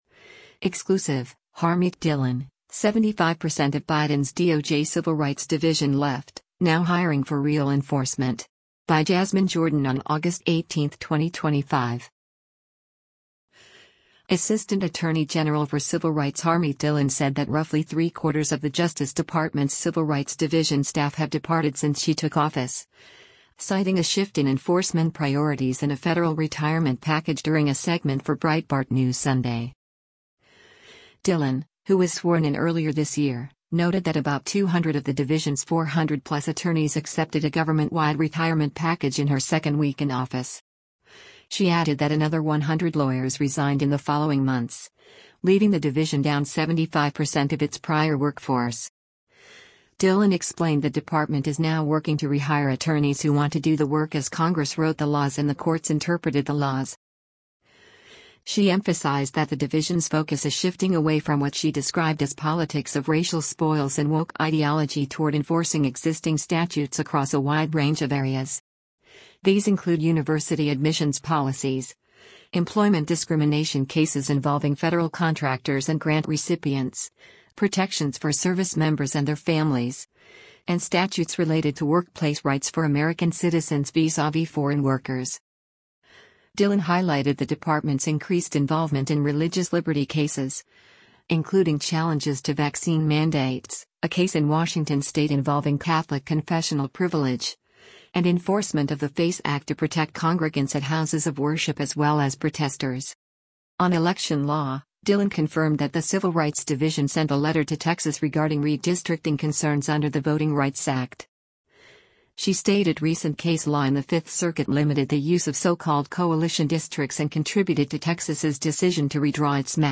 Breitbart News Sunday broadcasts live on SiriusXM Patriot 125 Sundays from 7:00 p.m. to 10:00 p.m. Eastern.